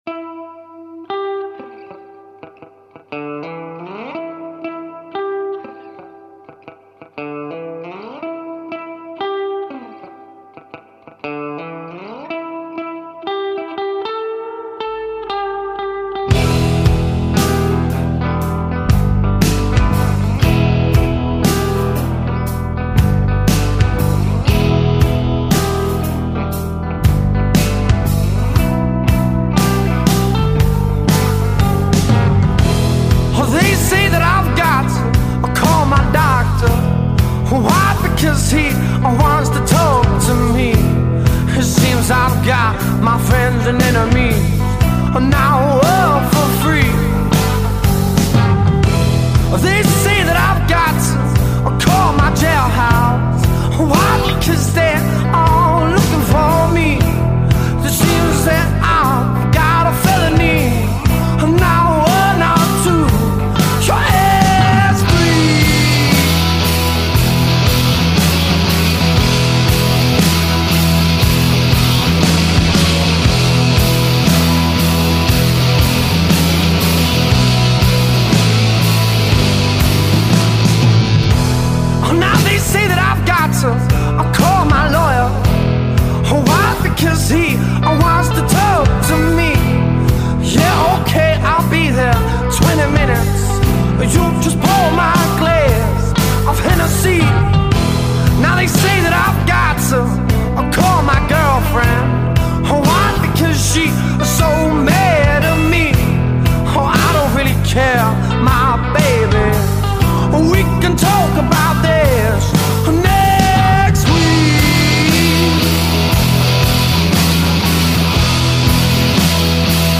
five-piece blues infused-rock band